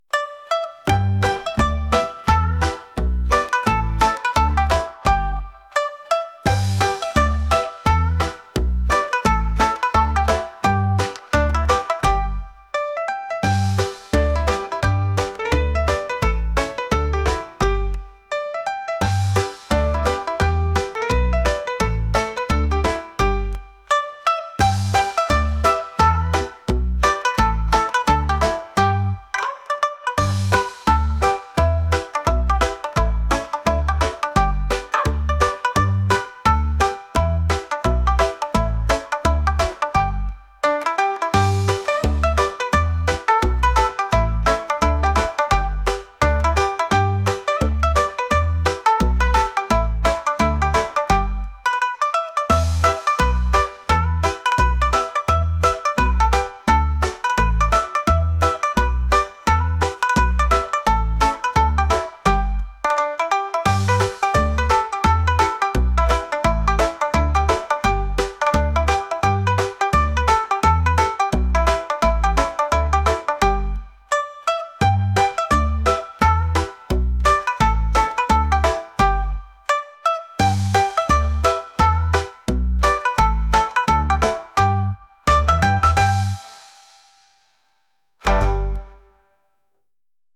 三味線を使ってのんびりしたような曲です。